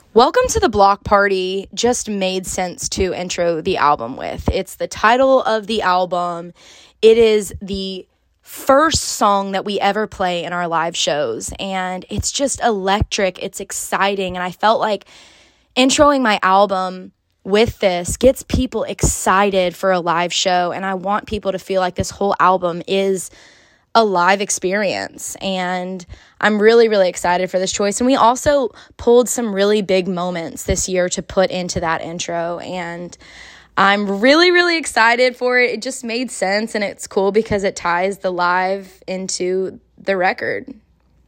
Audio / Priscilla Block says she put the intro track on her debut album, Welcome To The Block Party, because it ties her live show into her record.